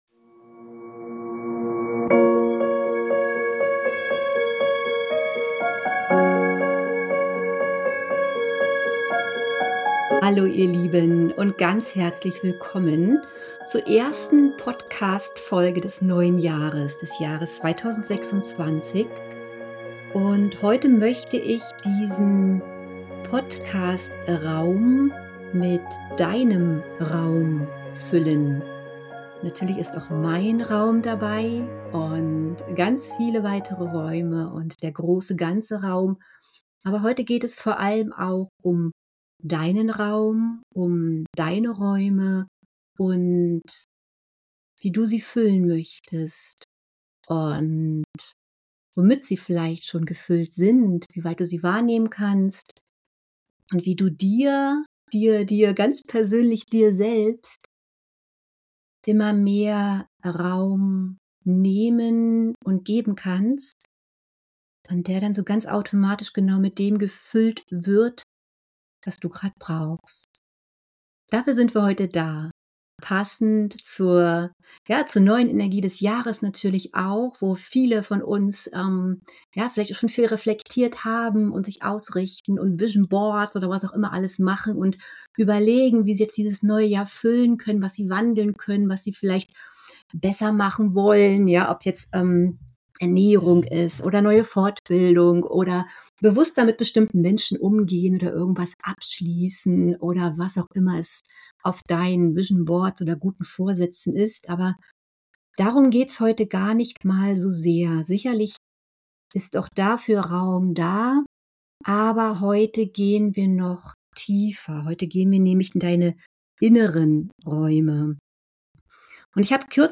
Mit achtsamen Impulsen, persönlichen Erfahrungen, ayurvedischen Bezügen und einer kraftvollen, meditativen Übung führe ich dich in dieser Episode sanft durch deine inneren Räume – Kopf, Kehle, Herz, Atem und Becken – und lade dich ein, wahrzunehmen, zu erforschen, zu hinterfragen und zu erkennen.